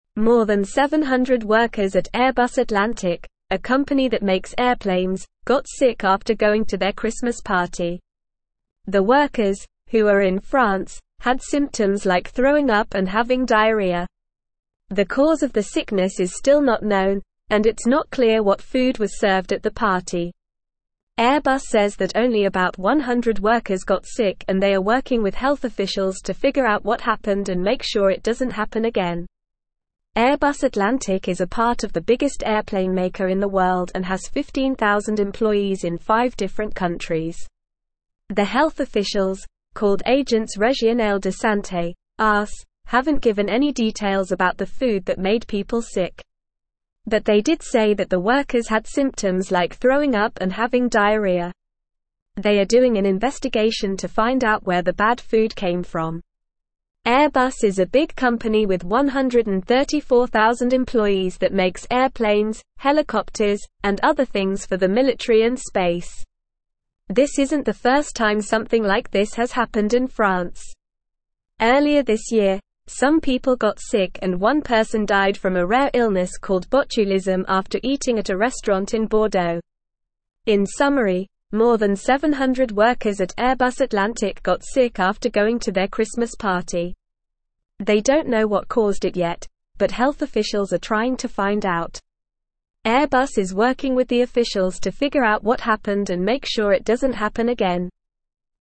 Normal
English-Newsroom-Upper-Intermediate-NORMAL-Reading-Over-700-Airbus-staff-fall-ill-after-Christmas-dinner.mp3